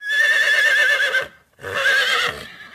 Kuda_Suara.ogg